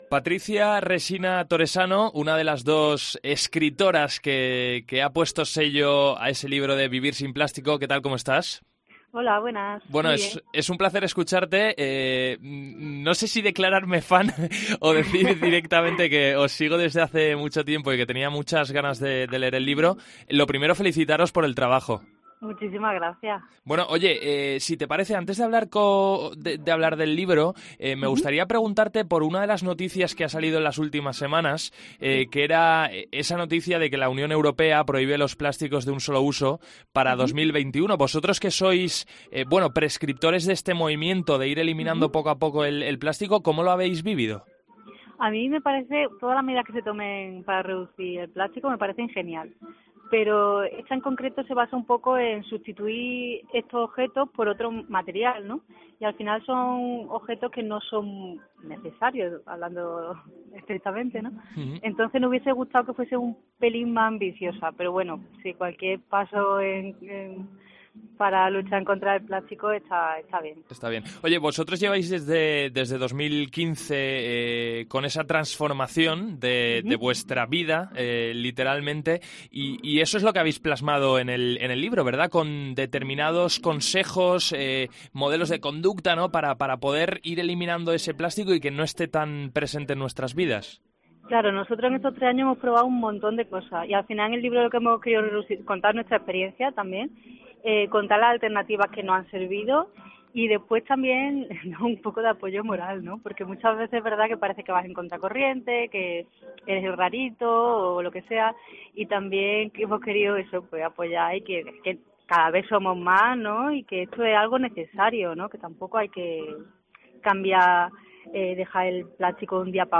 Entrevista a los autores de 'Vivir sin plástico'